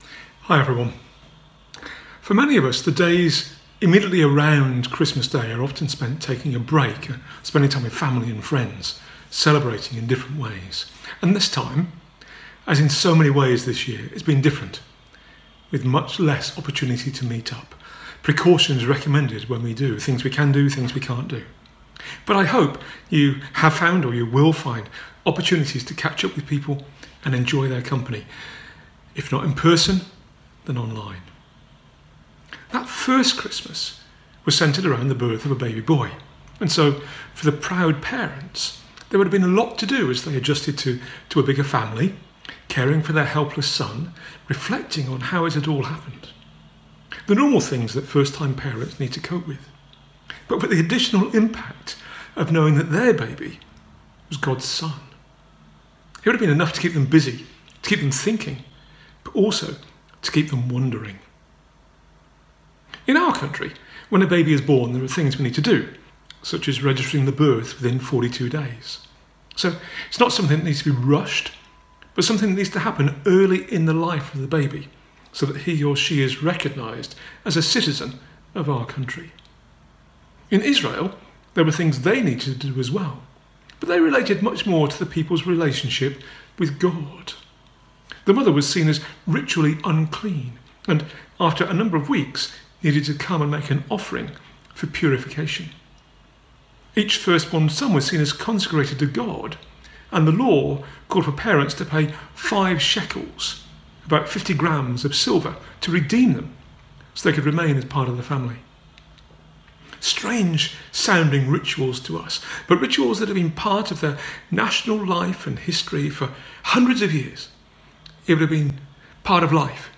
A talk from the series "Missionary Discipleship." Apologies - we didn't record the start of the talk.